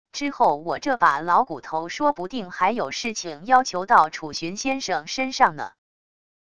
之后我这把老骨头说不定还有事情要求到楚旬先生身上呢wav音频生成系统WAV Audio Player